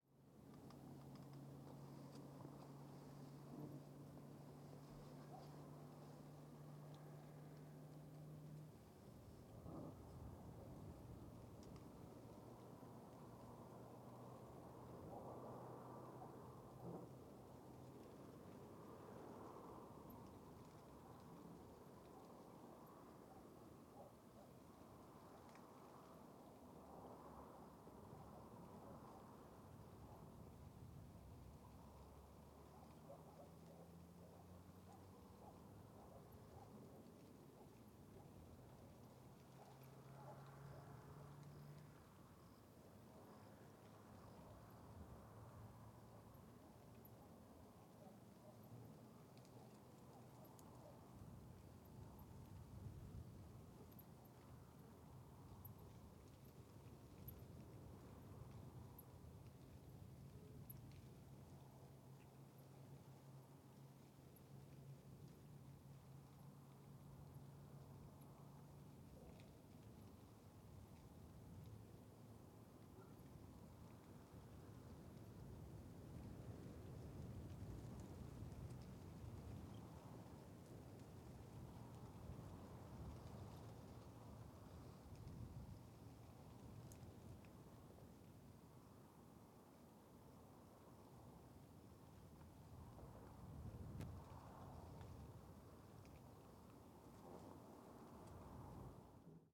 Arquivo de Ambiência Urbana - Coleção Sonora do Cerrado
Ambiencia no Alto do Morro da Baleia com Ruidos da Rodovia para Sao Jorge
Mono
CSC-04-147-GV - Ambiencia no Alto do Morro da Baleia com Ruidos da Rodovia para Sao Jorge.wav